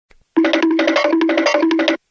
Catégorie: Bruitages